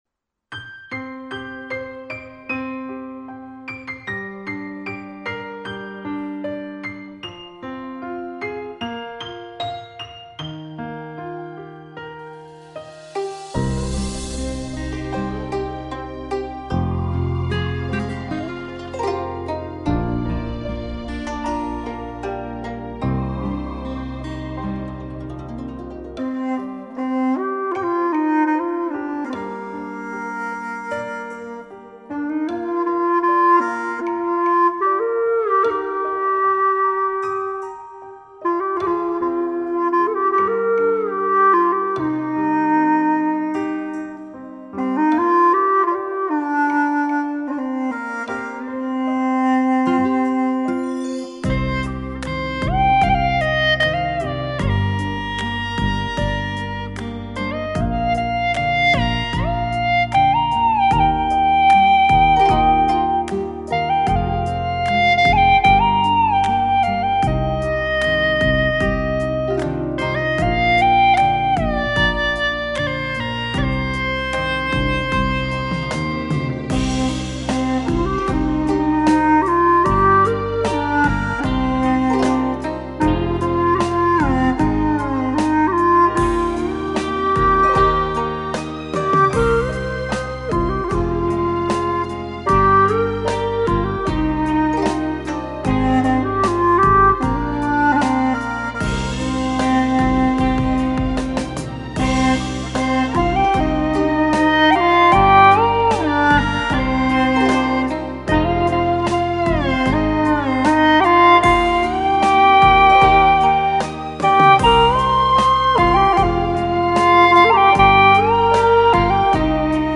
曲类 : 流行
古风作品
【大小降E】